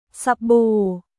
สบู่　サ・ブー